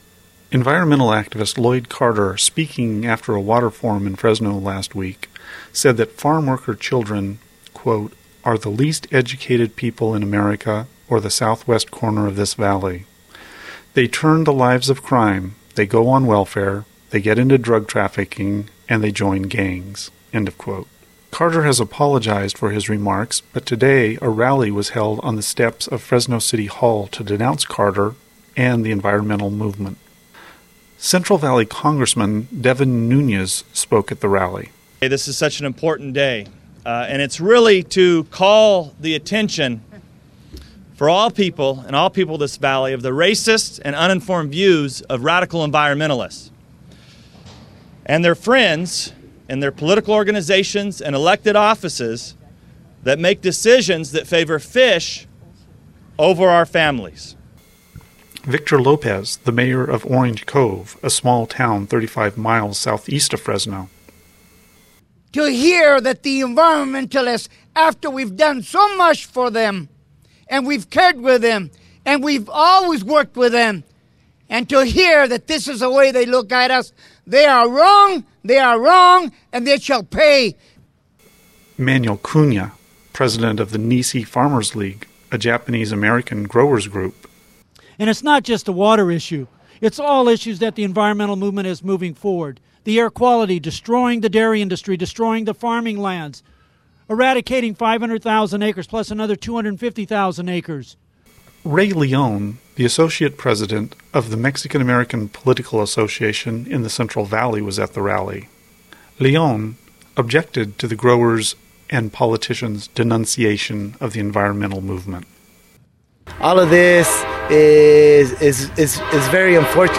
A 2:41 minute audio of speakers from the rally is below.
§2:42 minute Audio Report on event